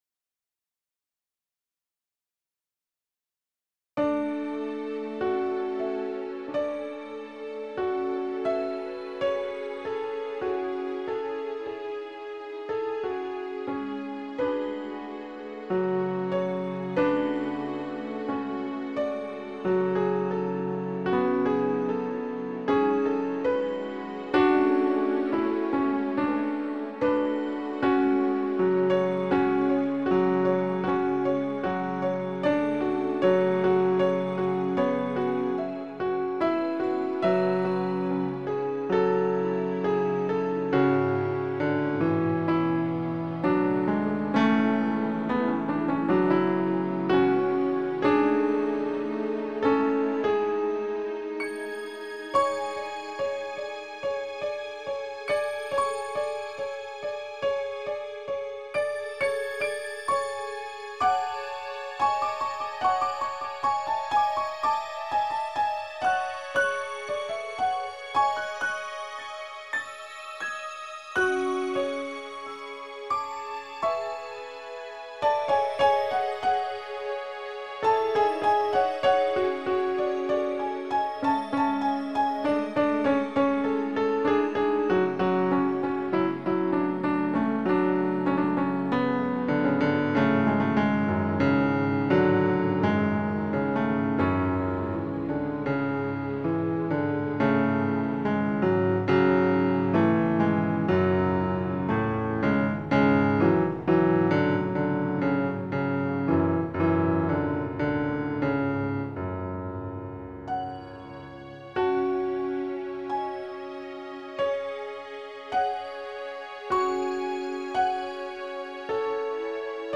Posted in Classical, Other Comments Off on